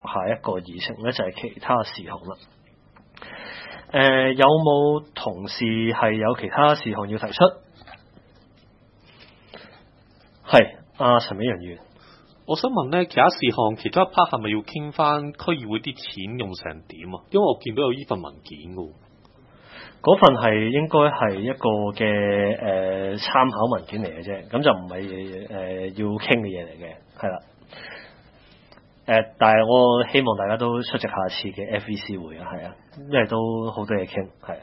南区区议会大会的录音记录
南区区议会会议室